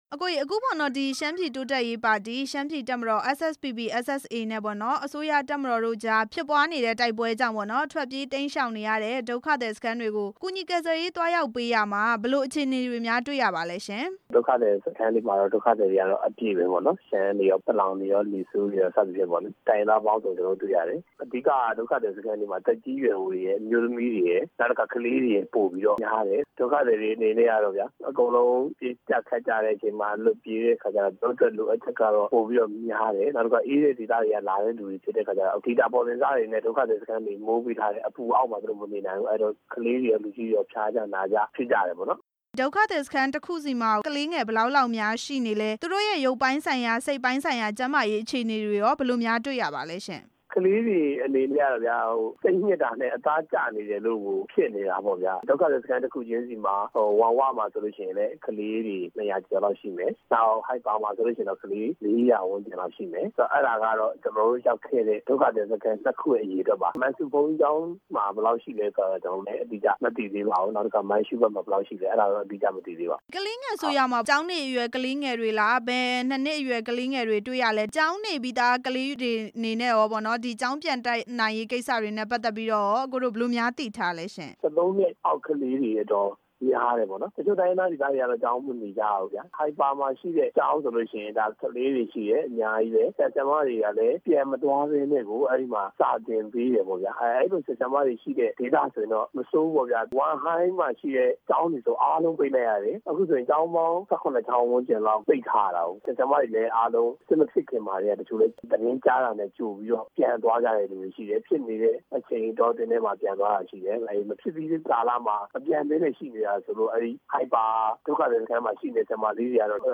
ဒုက္ခသည်စခန်းက ကလေးငယ်တွေအခြေအနေ မေးမြန်းချက်